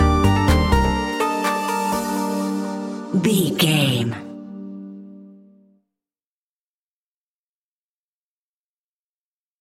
Aeolian/Minor
groovy
uplifting
driving
energetic
drum machine
synthesiser
bass guitar
funky house
nu disco
upbeat
instrumentals